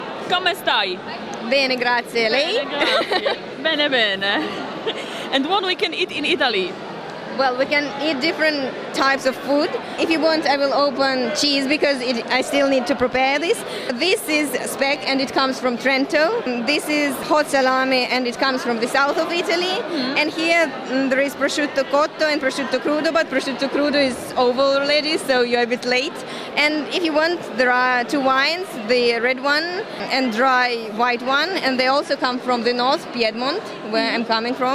Mówią uczestnicy Forum